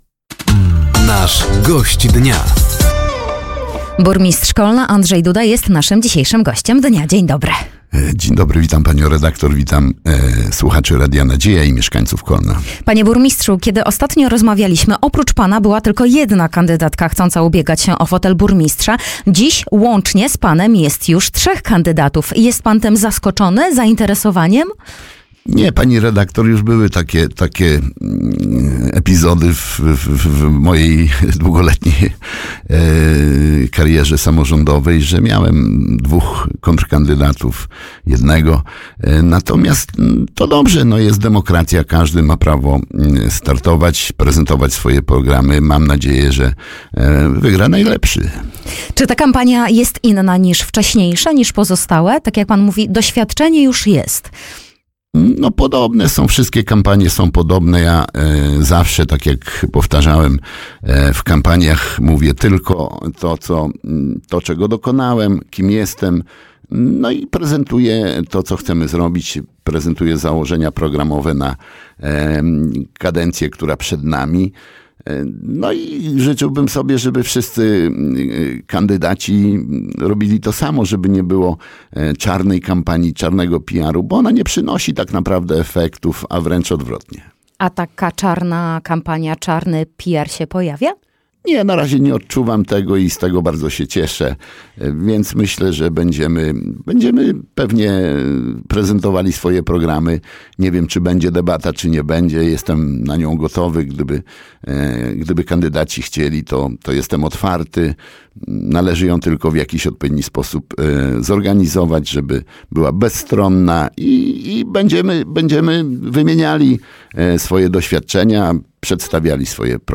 Gościem Dnia Radia Nadzieja był Andrzej Duda, Burmistrz Kolna. Tematem rozmowy była trwająca kampania wyborcza, ogłoszony przetarg na modernizację ciepłowni oraz rozpoczęty remont dróg w mieście.